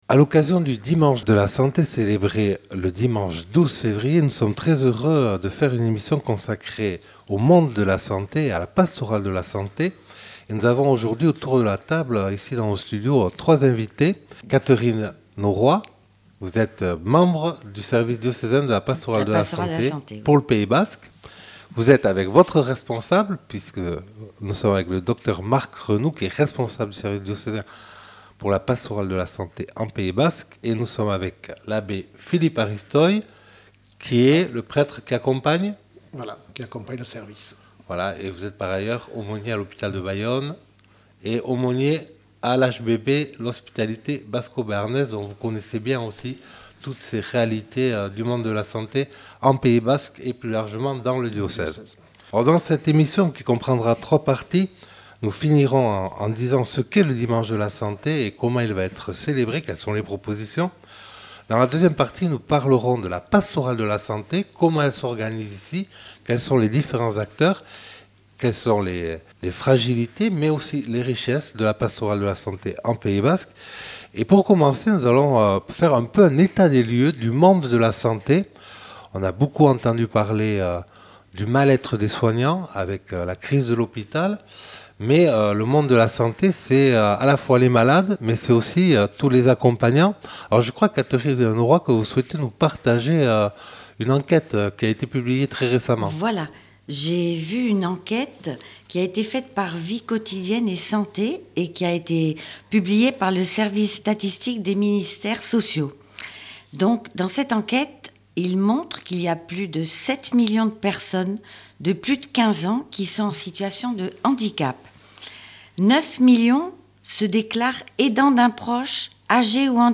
Table-ronde